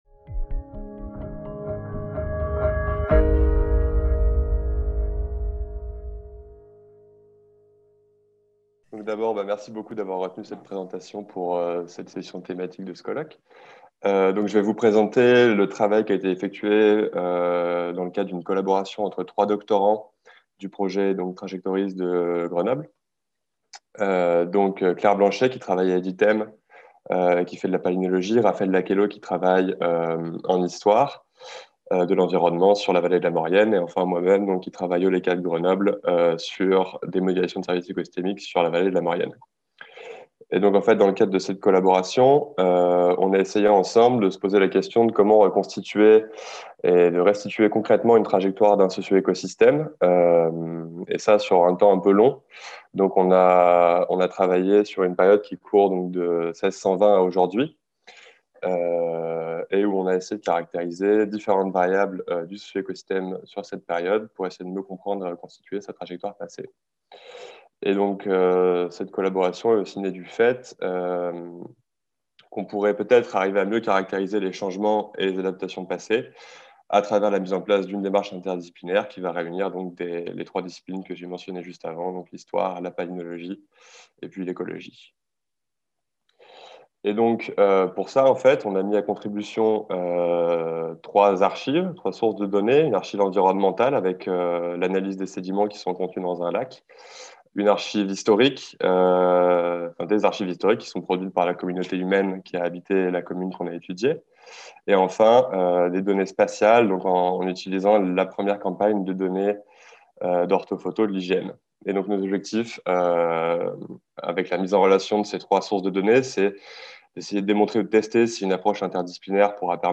5e colloque des Zones Ateliers – CNRS - 2000-2020, 20 ans de recherche du Réseau des Zones Ateliers